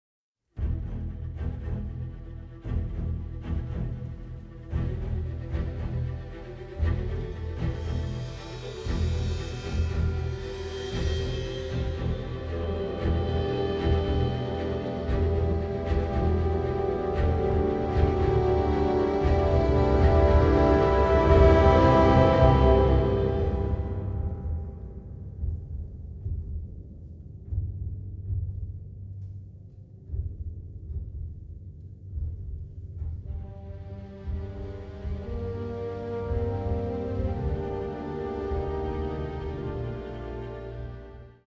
ominous score